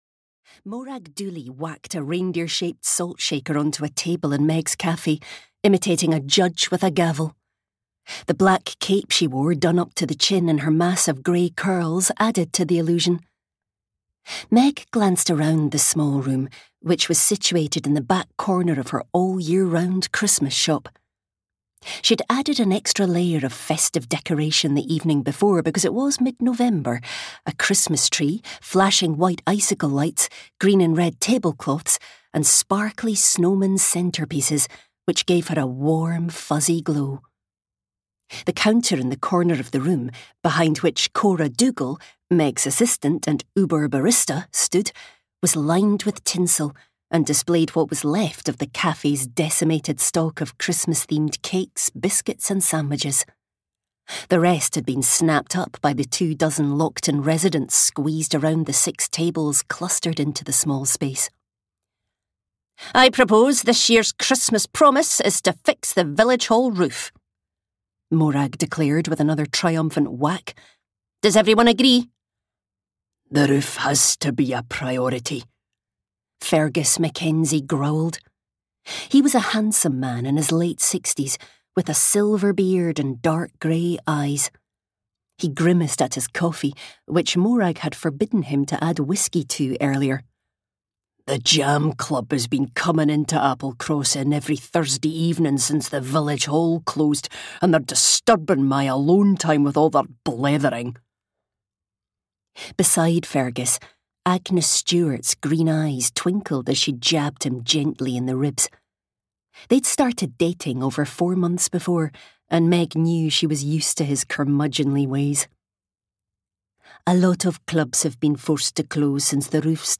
If Every Day Was Christmas (EN) audiokniha
Ukázka z knihy